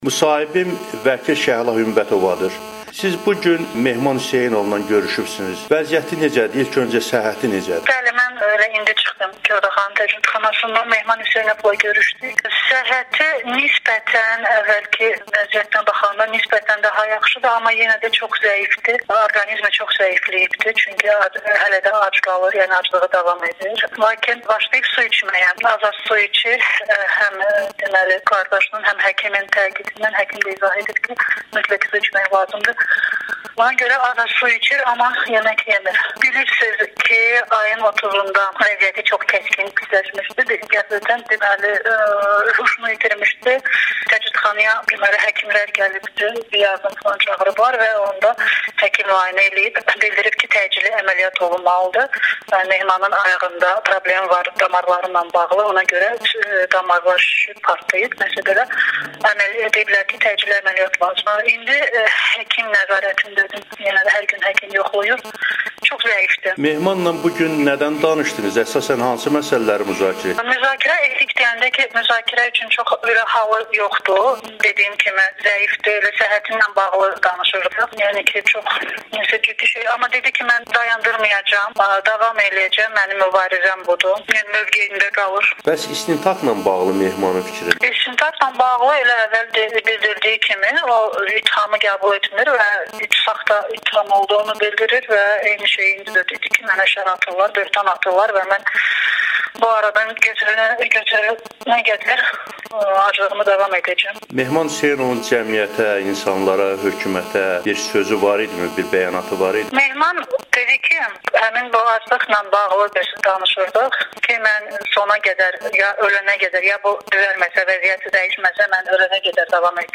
Amerikanın Səsinə müsahibəsində